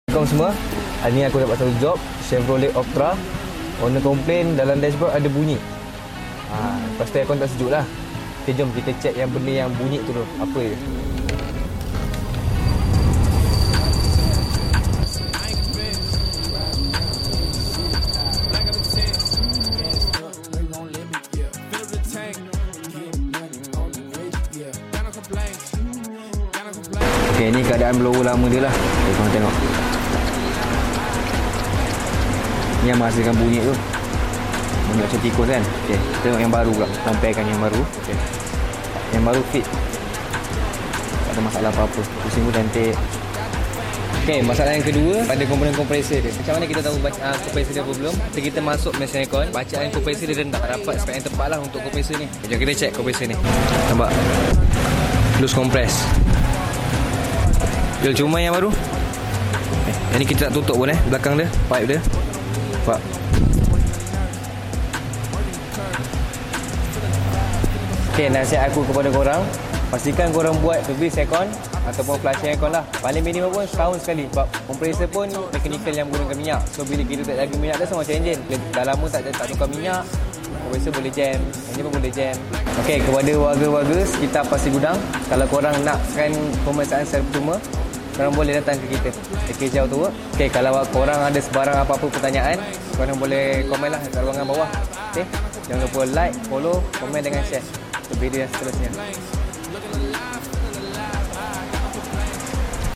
Bunyi tikus dalam kereta ? sound effects free download